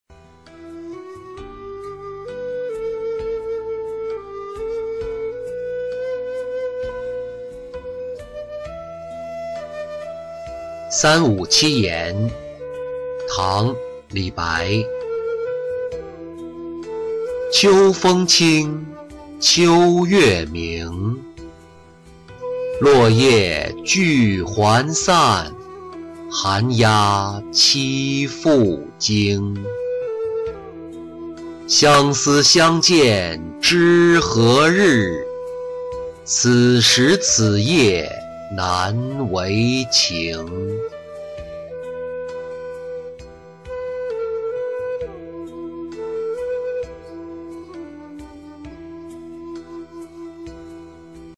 秋词二首·其二-音频朗读